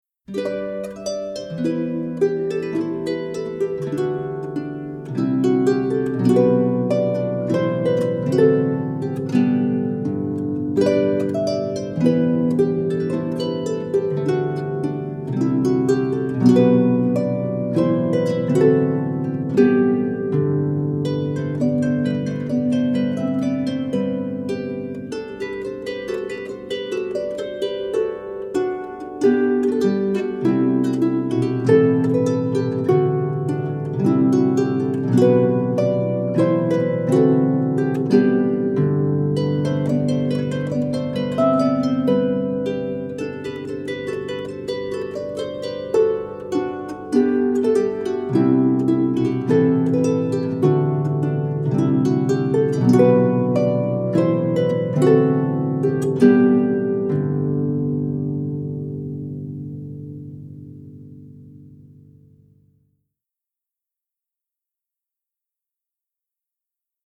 traditional Welsh song